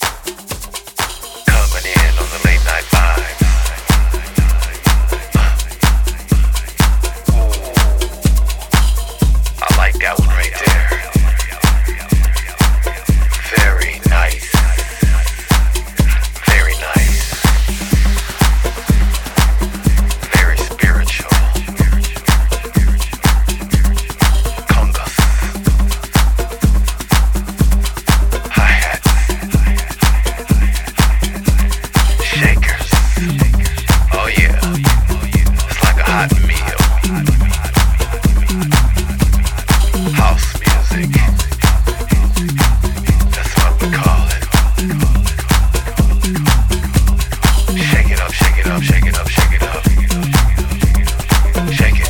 deep house single